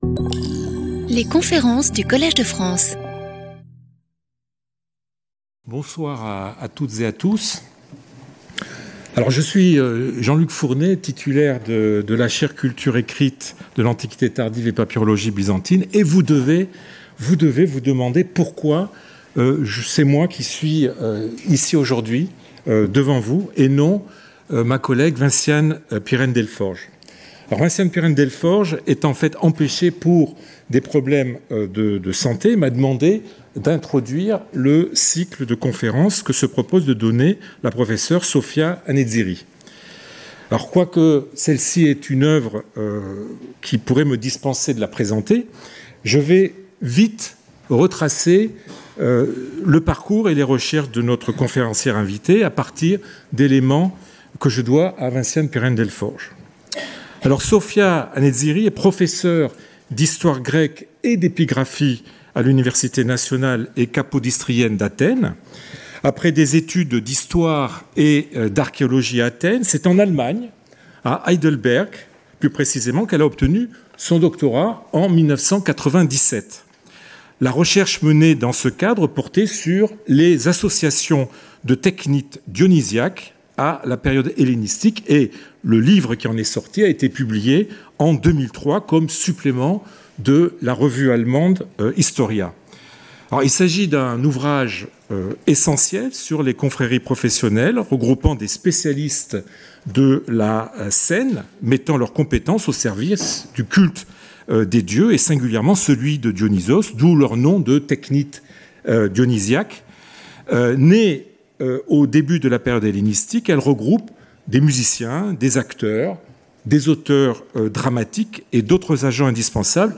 Lecture audio
Conférencier invité